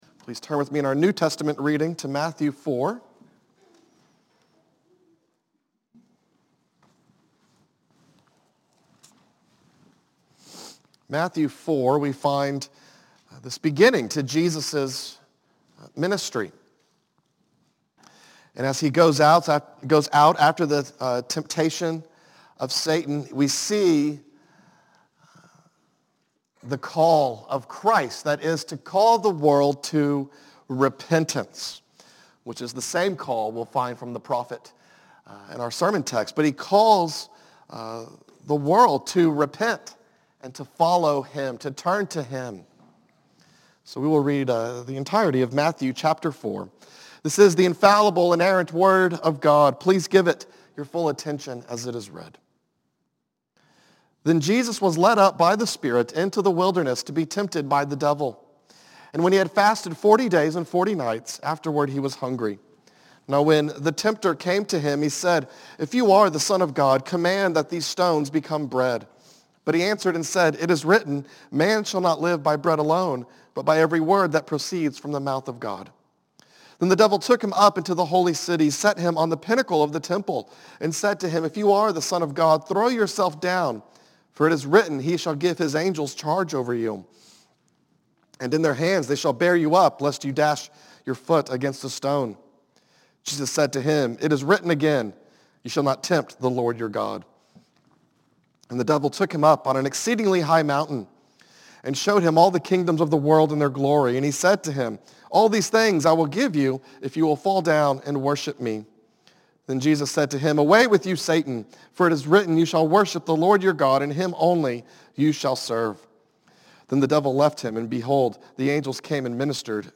A message from the series "Zechariah."